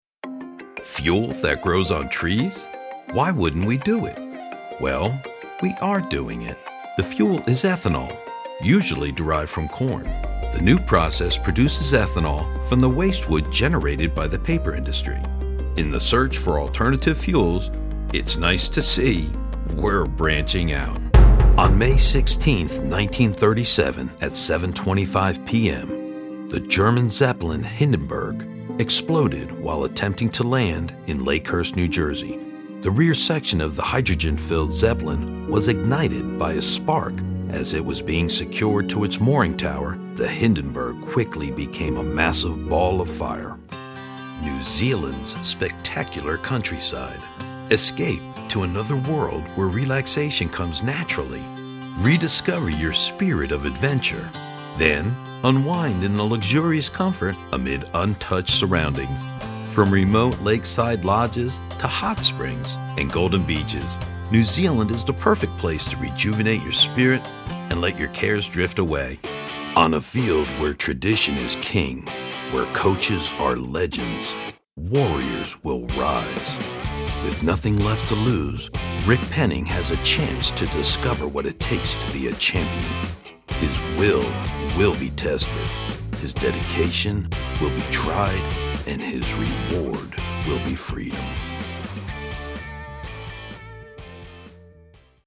Deep english speaking voice, authorative, manly, believable, honest, raspy, rugged.
Sprechprobe: Industrie (Muttersprache):